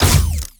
GUNAuto_Plasmid Machinegun C Single_02_SFRMS_SCIWPNS.wav